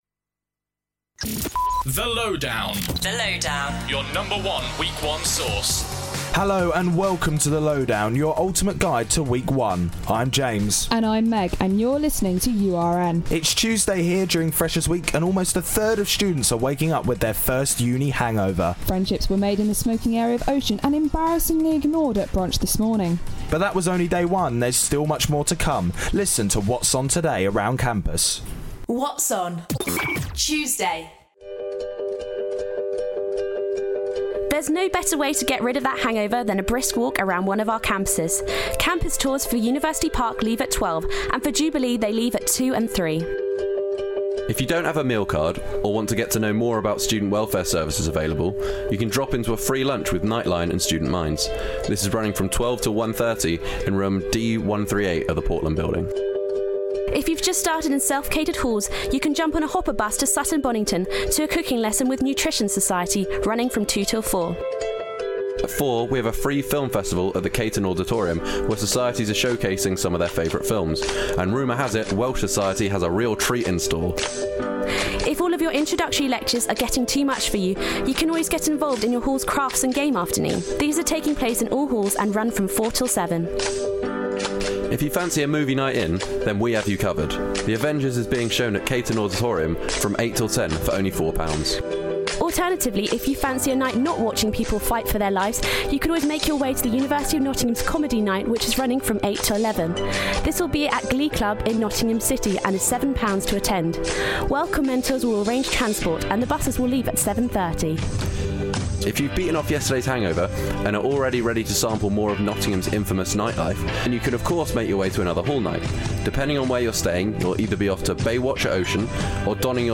With interviews